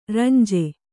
♪ ranje